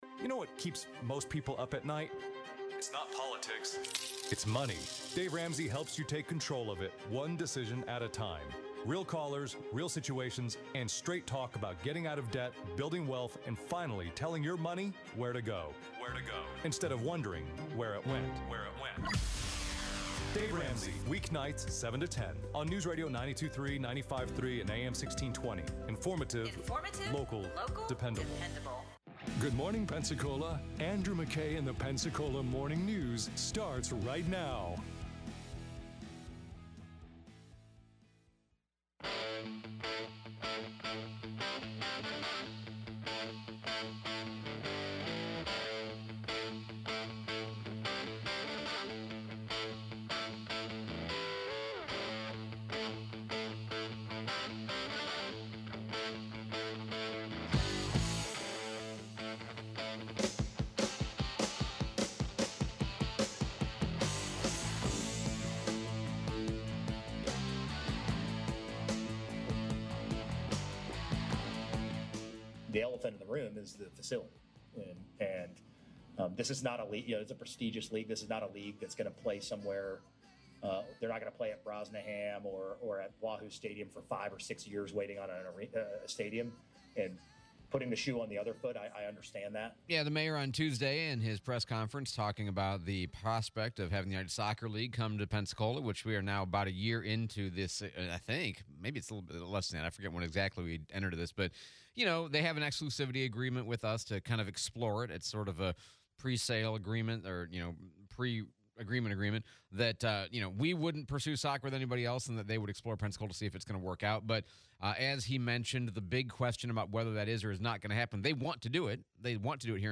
Mayor's Reeves press conference on Soccer League, Bay Center upgrades, elected or appointed Superintendent, replay of Mayor Reeves interview